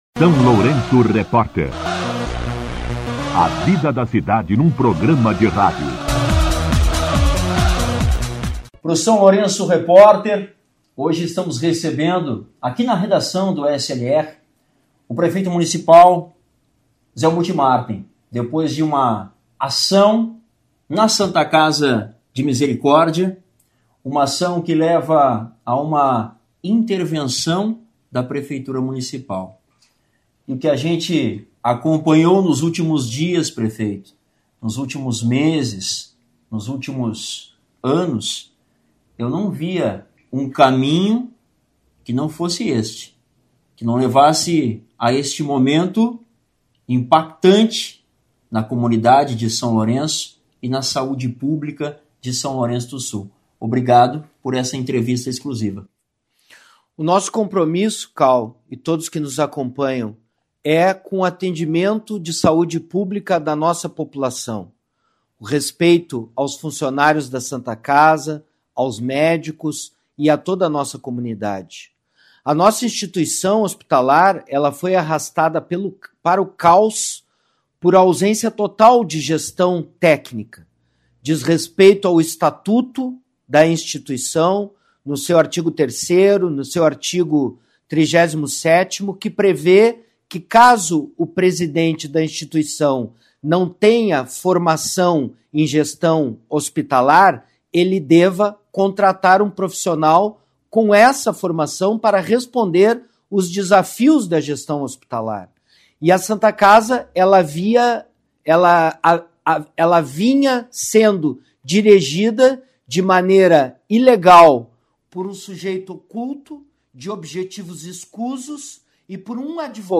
O prefeito Zelmute Marten concedeu entrevista ao SLR na manhã desta terça-feira (29) para explicar à comunidade como funcionará a intervenção na Santa Casa de Misericórdia de São Lourenço do Sul.